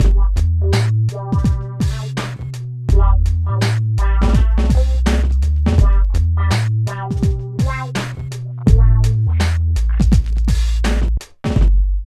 44 LOOP   -L.wav